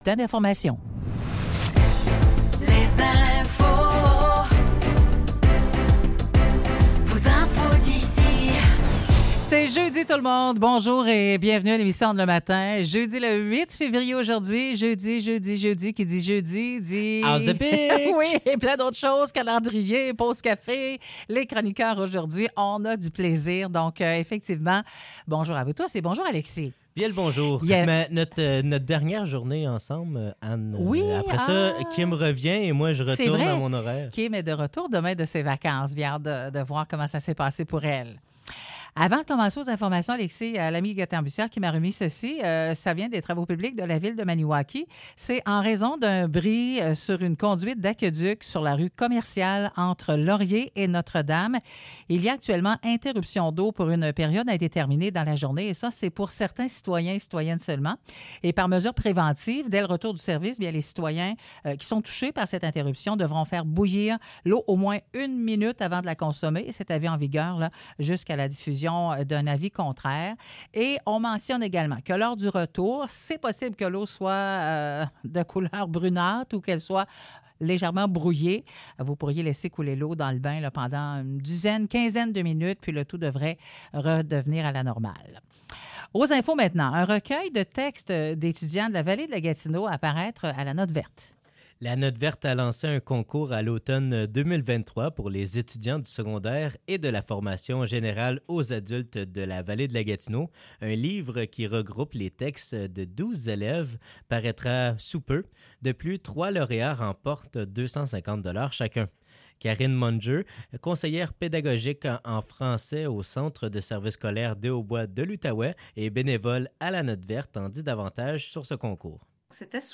Nouvelles locales - 8 février 2024 - 9 h